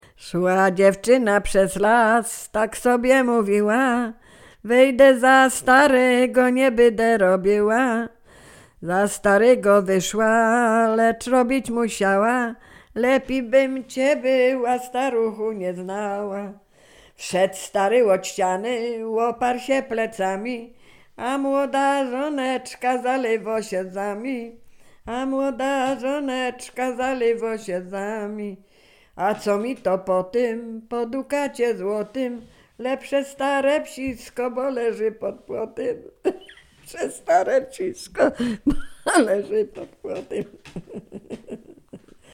Sieradzkie
Przyśpiewki
liryczne miłosne weselne